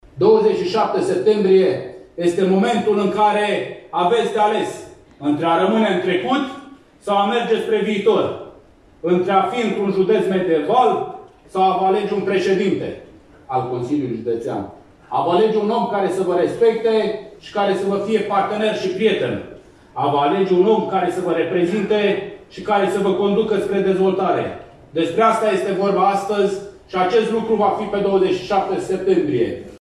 Echipa liberală de la Neamț a organizat un eveniment prin care și-a prezentat programul de guvernare locală, intitulat ”Bunăstare și Respect – Se poate și la Neamț”.
Candidații PNL la primăriile de municipii, orașe și comune din județ au fost prezentați de Mugur Cozmanciuc, candidatul PNL pentru președinția Consiliului Județean Neamț: